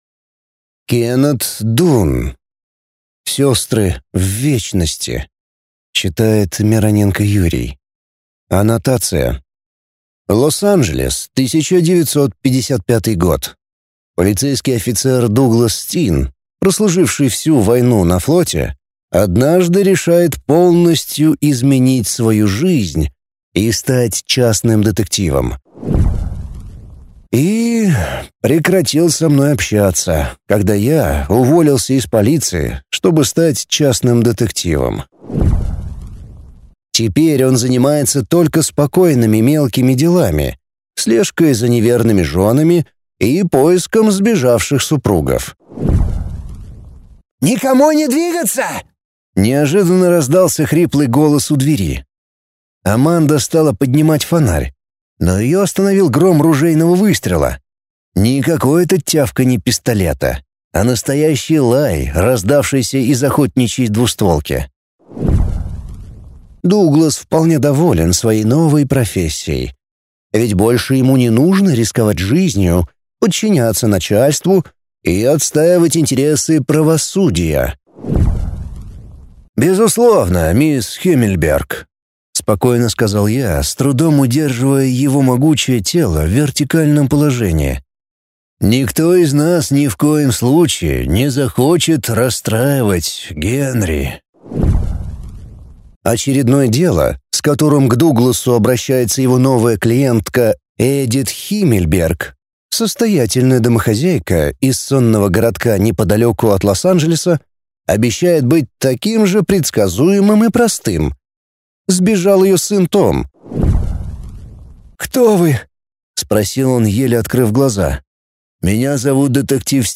Аудиокнига Сестры в вечности | Библиотека аудиокниг